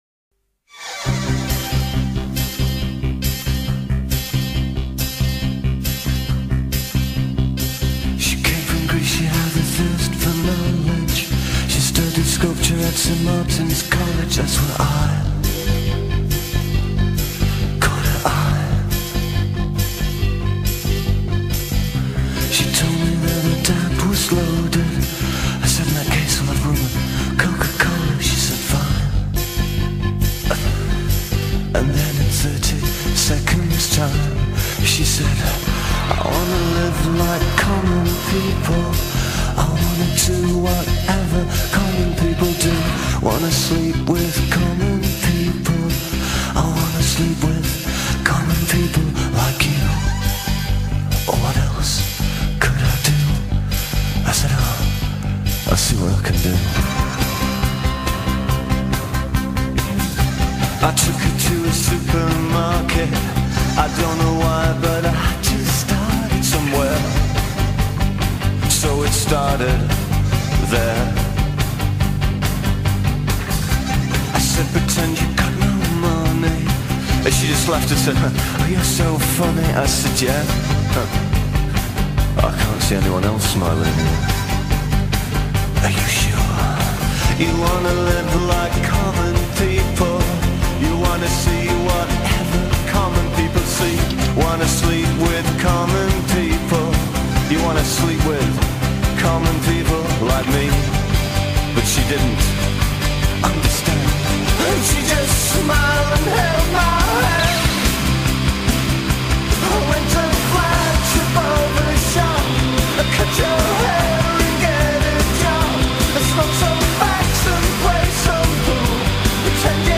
類型： 英倫搖滾， 藝術搖滾， 華麗搖滾， 獨立流行音樂